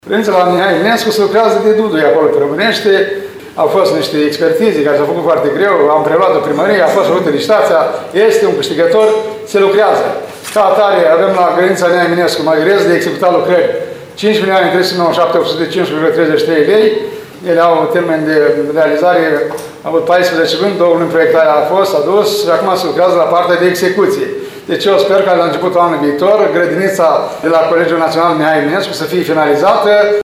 În ceea ce privește grădinița de la Colegiul Mihai Eminescu, primarul ION LUNGU a declarat că lucrările au demarat și că termenul de finalizare este începutul anului viitor.